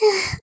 birdo_panting.ogg